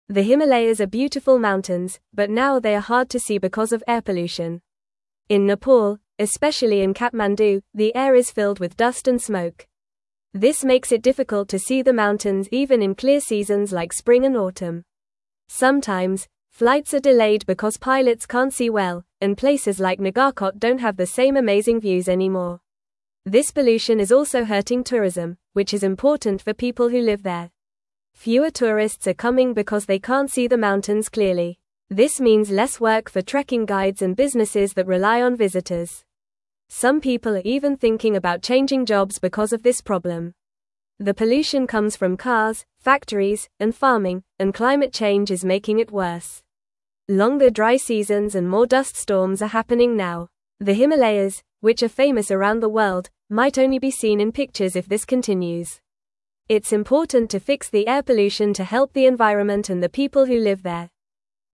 Fast
English-Newsroom-Lower-Intermediate-FAST-Reading-Dirty-Air-Hides-Beautiful-Himalayas-from-Everyone.mp3